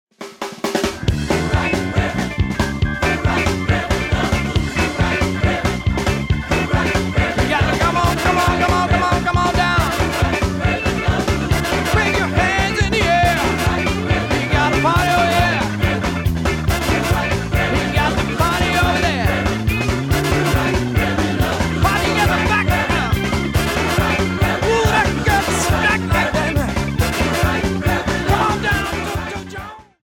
Texas blues singer-musician